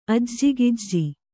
♪ ajjigajji